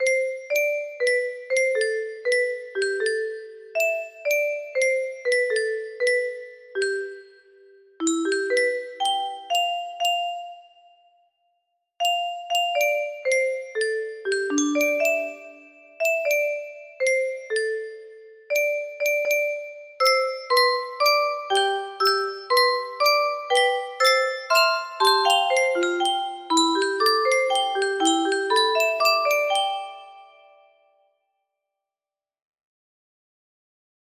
Carol music box melody
Grand Illusions 30 (F scale)